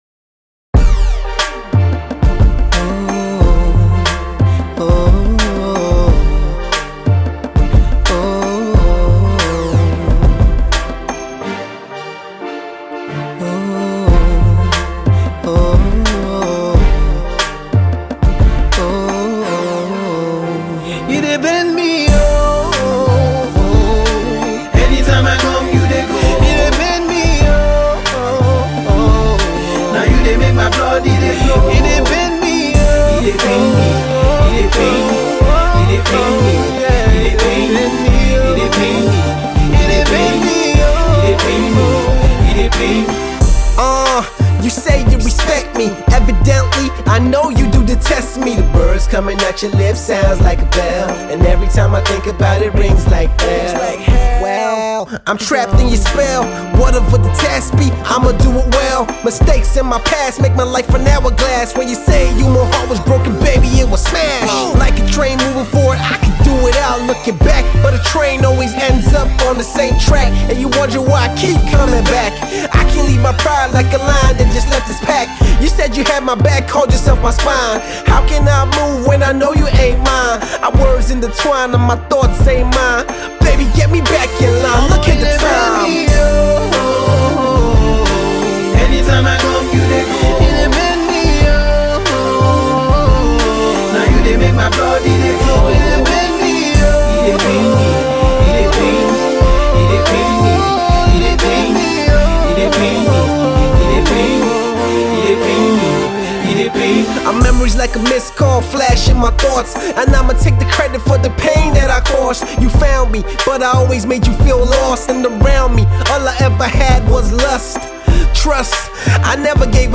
up-and-coming rapper
With his smooth fluid flow and good wordplay